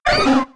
Cri de Grodoudou K.O. dans Pokémon X et Y.